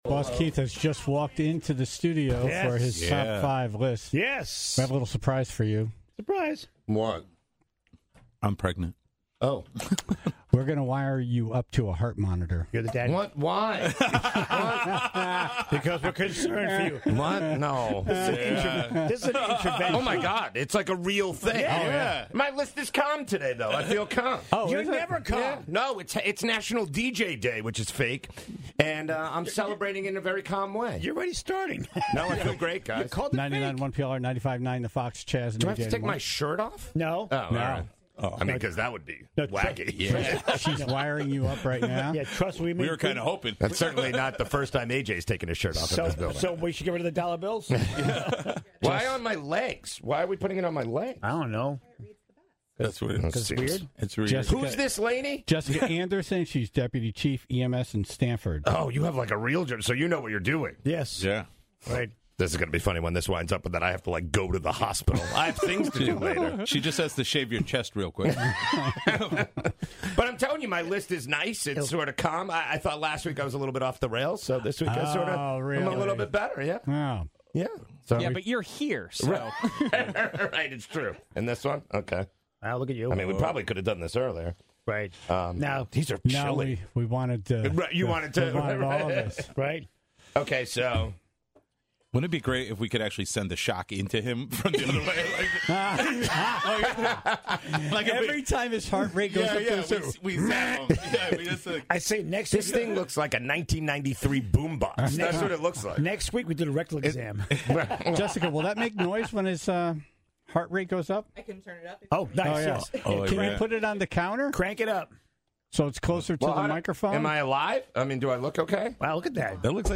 Now we can all hear how he works himself up into a frenzy, getting big mad about nonsense!